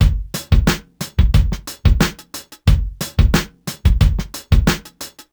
Index of /musicradar/sampled-funk-soul-samples/90bpm/Beats
SSF_DrumsProc1_90-02.wav